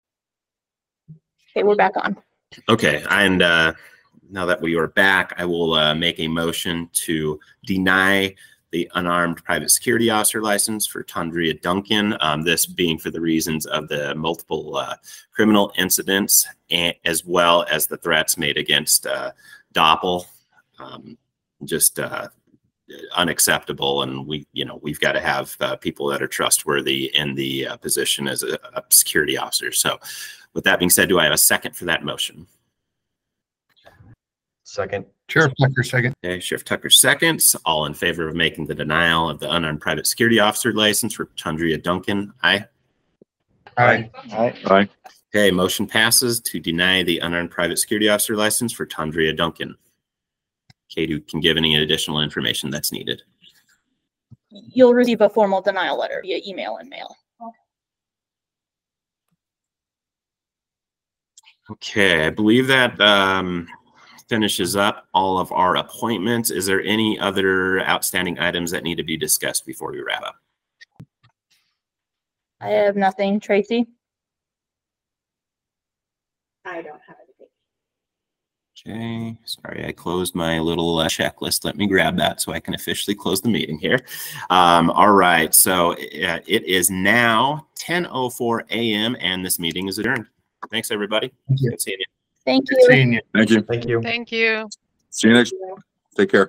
Security Services Licensing Board meeting
Electronic participation is planned for this meeting.
160 East 300 South - North Conference Room 1st floor